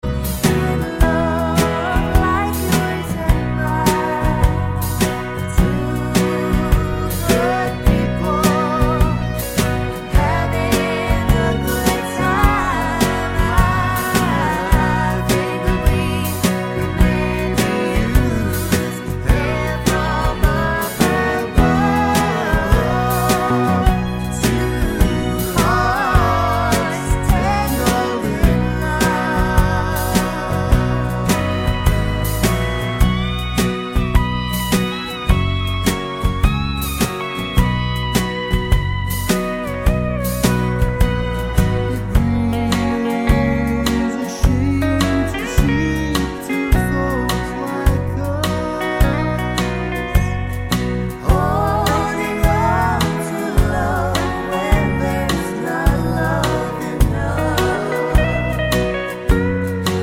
no Backing Vocals Country (Male) 3:00 Buy £1.50